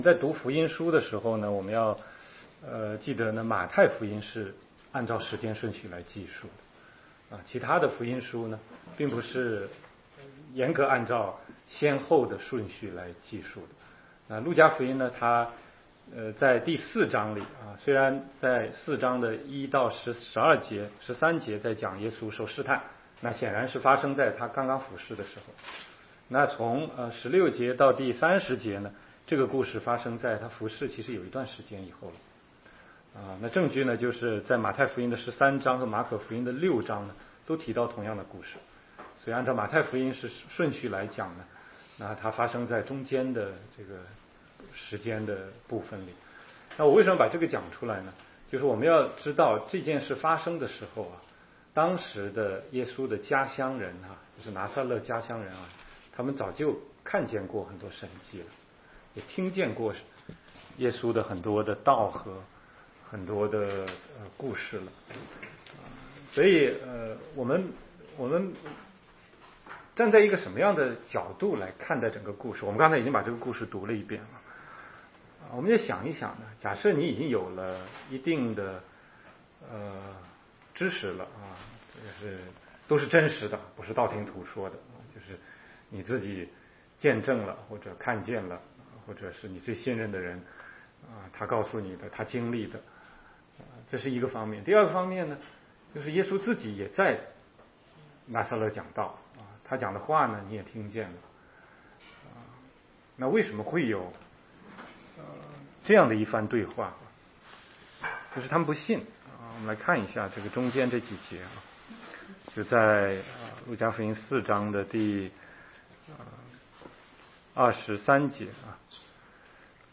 16街讲道录音 - 偏见与信心